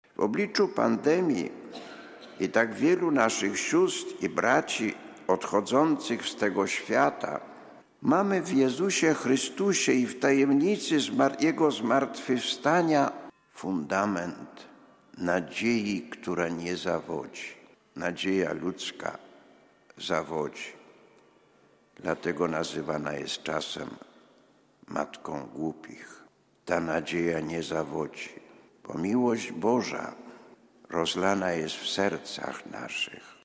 ’- W obecnych trudnych czasach potrzebujemy nadziei – powiedział biskup Paweł Socha podczas uroczystości Wigilii Paschalnej w gorzowskiej katedrze.
sobotnia-homilia.mp3